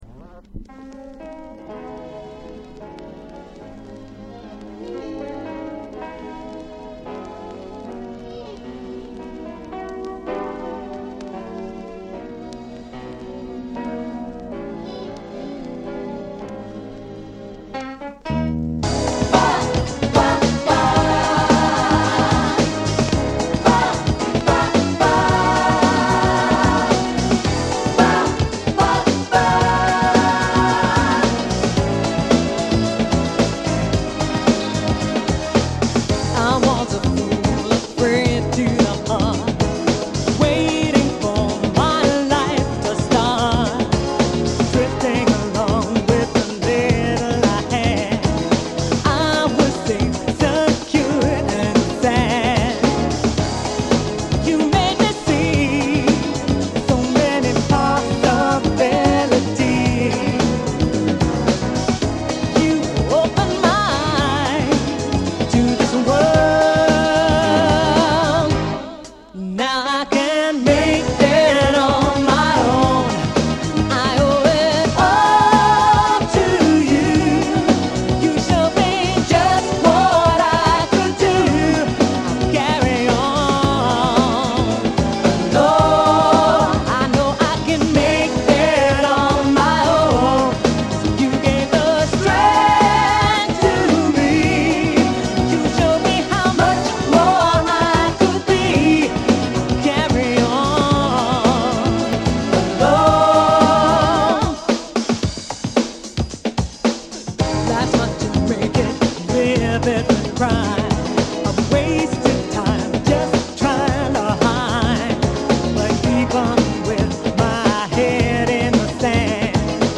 > TECHNO/HOUSE/BREAKS